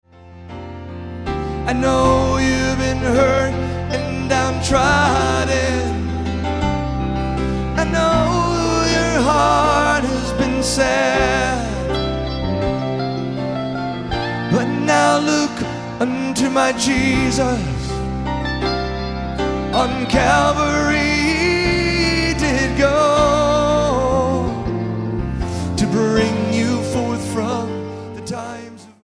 Style: MOR/Soft Pop Approach: Praise & Worship